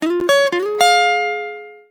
Huawei Bildirim Sesleri
Joyful